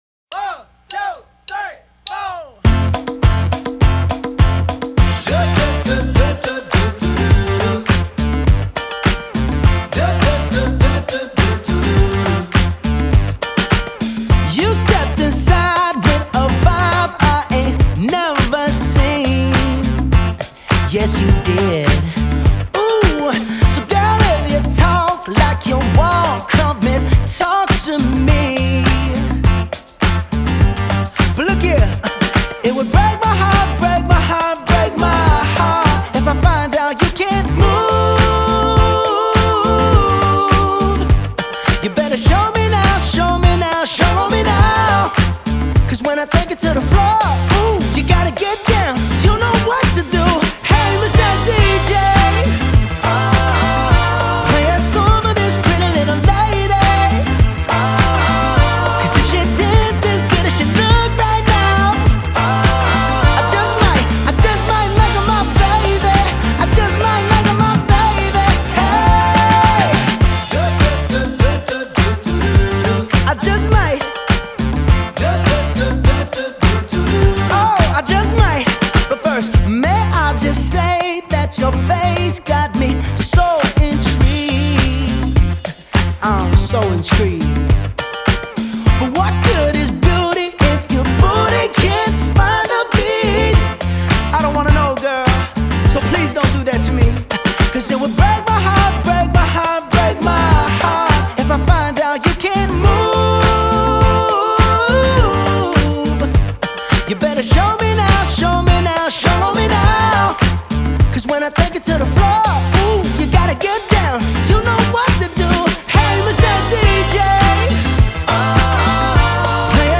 BPM103-103
Disco-Pop song for StepMania, ITGmania, Project Outfox
Full Length Song (not arcade length cut)